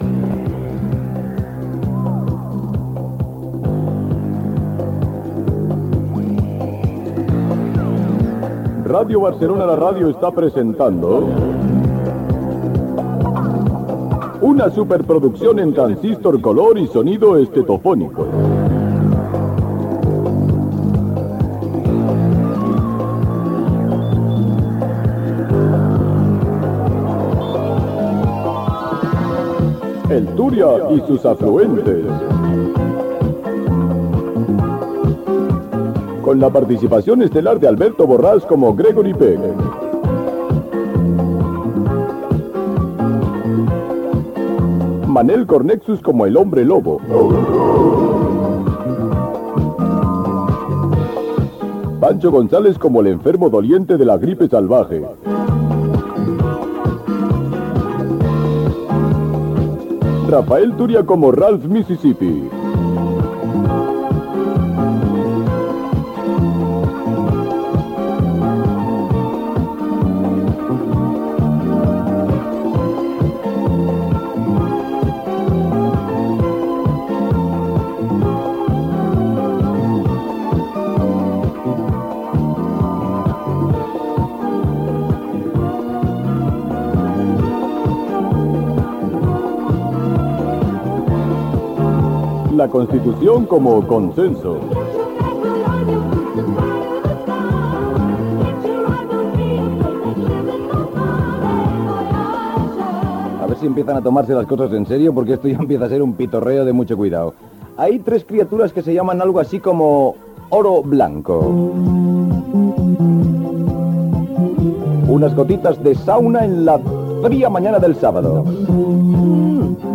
Inici del programa i presentació d'un tema musical.
Musical
FM